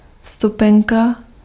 Ääntäminen
Synonyymit ticket Ääntäminen France: IPA: [bi.jɛ] Haettu sana löytyi näillä lähdekielillä: ranska Käännös Ääninäyte Substantiivit 1. lístek {m} Muut/tuntemattomat 2. vstupenka {f} Suku: m .